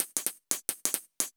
Index of /musicradar/ultimate-hihat-samples/175bpm
UHH_ElectroHatA_175-04.wav